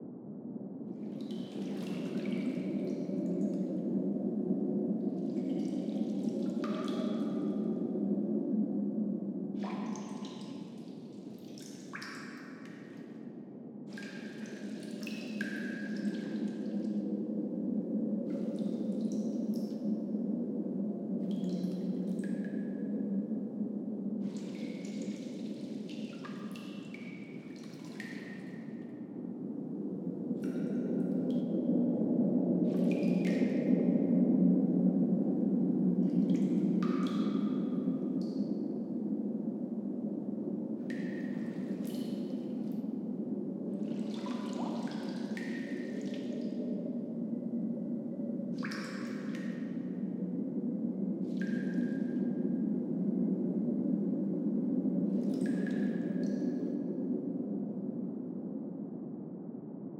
BGS Loops
Cave.wav